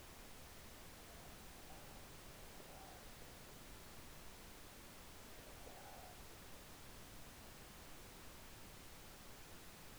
Y junto a ese vídeo, una psicofonía registrada durante la misma madrugada, cuando todo estaba en silencio.
psicofonia_spectral.wav